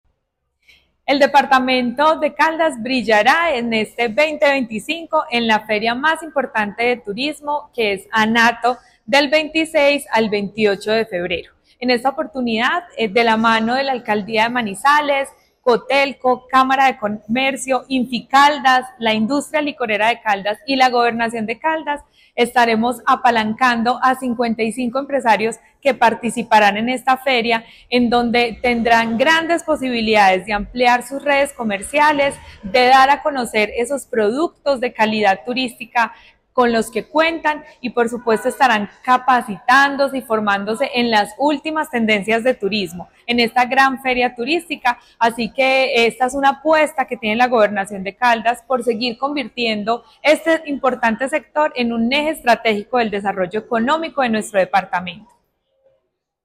Daissy Lorena Alzate, secretaria de Desarrollo, Empleo e Innovación de Caldas.